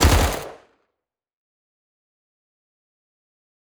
Monster_03_Atk.wav